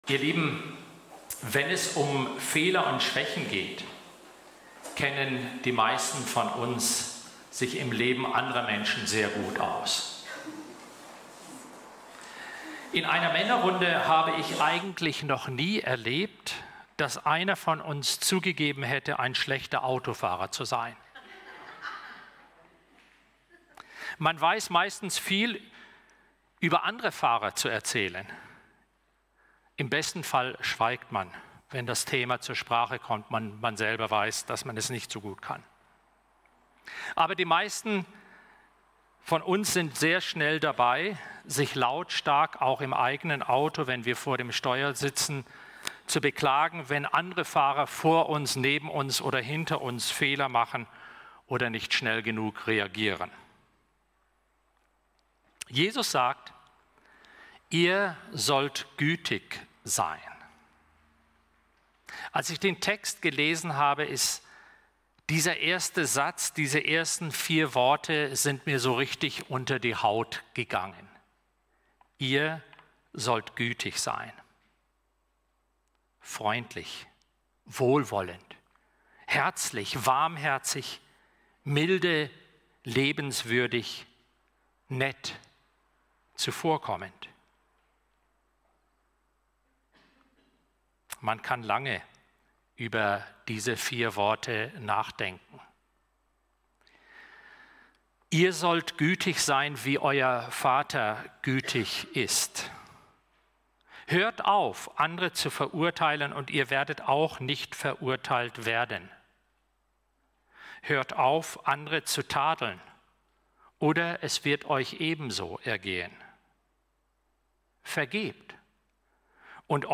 Gottesdienst_-Splitter-und-Balken-Lukas-6-36-42.mp3